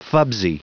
Prononciation du mot fubsy en anglais (fichier audio)
Prononciation du mot : fubsy